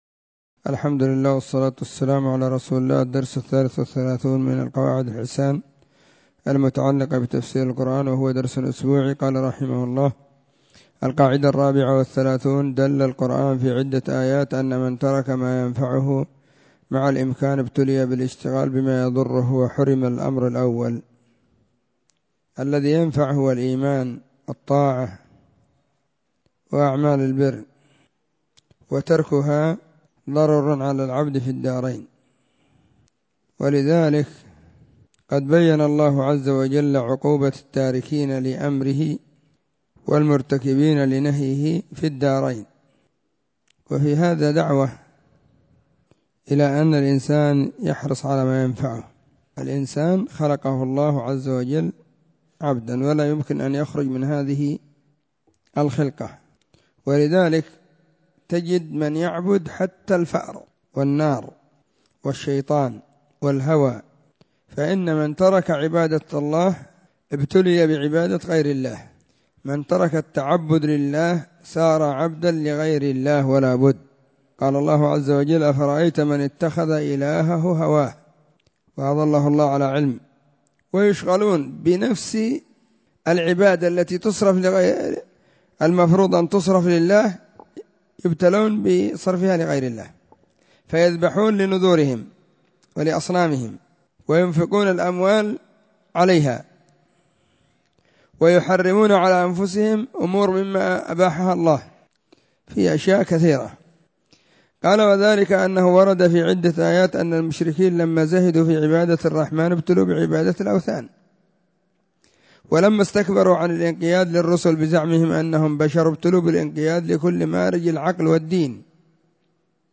🕐 [بعد صلاة الظهر في كل يوم الخميس]
📢 مسجد الصحابة – بالغيضة – المهرة، اليمن حرسها الله.